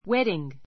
wedding A2 wédiŋ ウェ ディン ぐ 名詞 ❶ 結婚 けっこん 式 ⦣ ふつう教会での式の後, 新婦の家で行われる披露 ひろう パーティーまで含 ふく めて wedding という. marriage ❷ 結婚記念日 a silver [golden] wedding a silver [golden] wedding 銀[金]婚 こん 式 ⦣ それぞれ25周年, 50周年の結婚記念日.